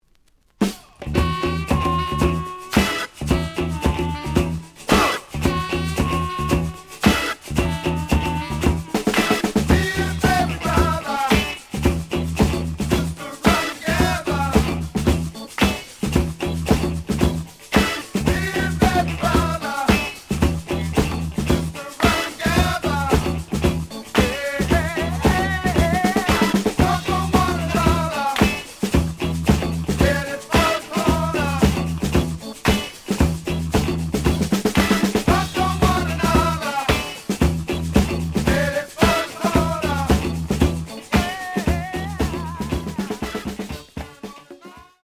試聴は実際のレコードから録音しています。
●Genre: Funk, 70's Funk